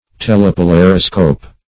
Search Result for " telepolariscope" : The Collaborative International Dictionary of English v.0.48: Telepolariscope \Tel`e*po*lar"i*scope\, n. [Gr.